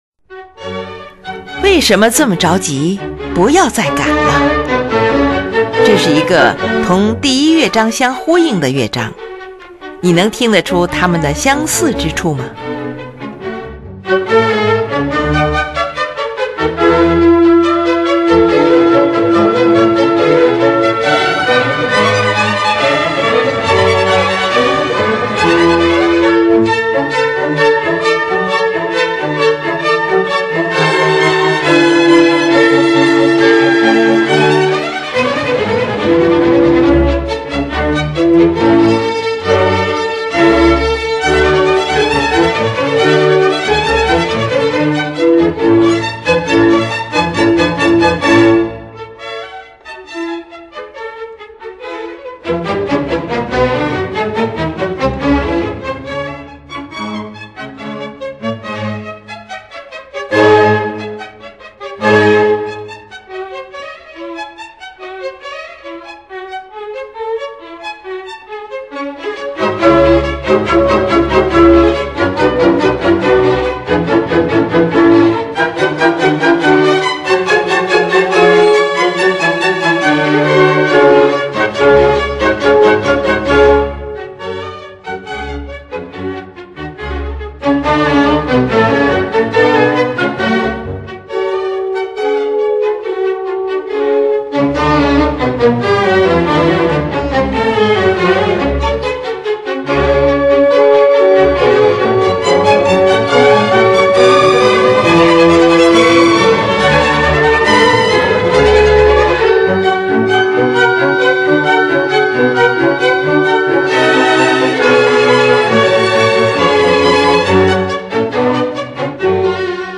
这个乐章和第四乐章都充满了悲怆的戏剧性情绪。
第四乐章，前面说过这个乐章的情绪与第一乐章极其类似，也正因为这样，使整个交响曲在很大程度上得到了统一。
既典雅庄重，又激动人心。